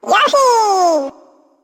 One of Yoshi's voice clips in Mario Party 6